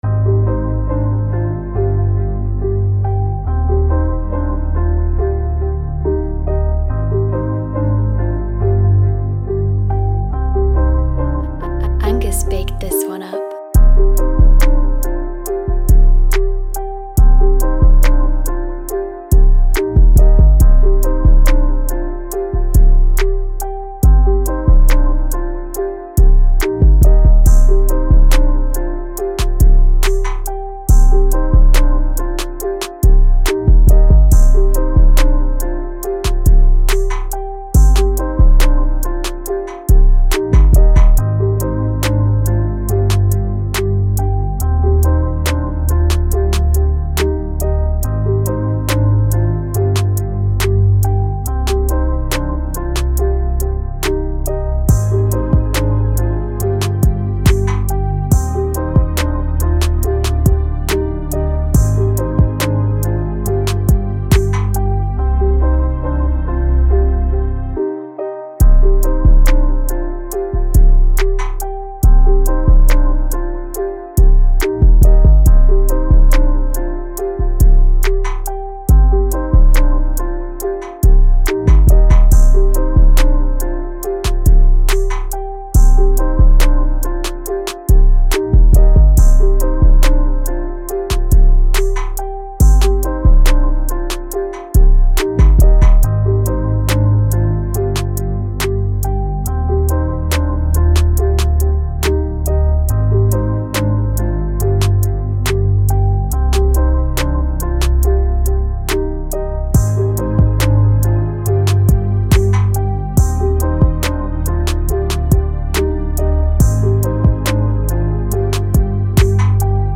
Beats